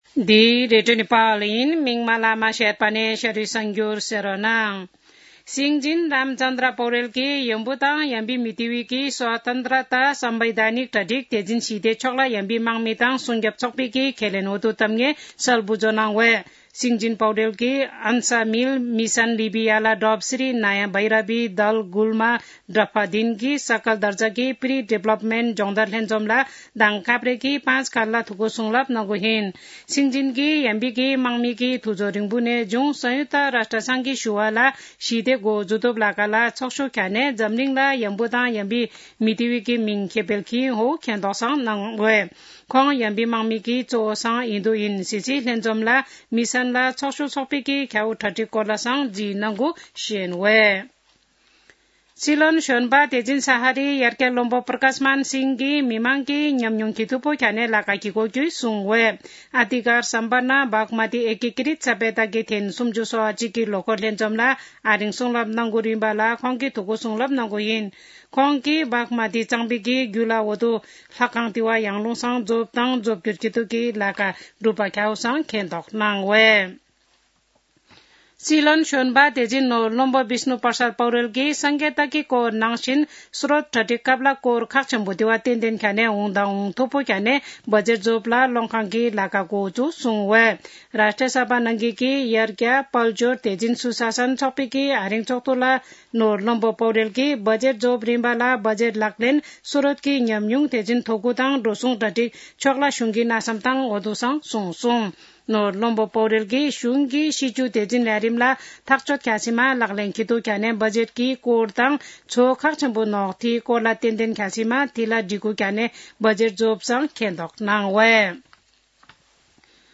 शेर्पा भाषाको समाचार : २८ चैत , २०८१
sharpa-news-.mp3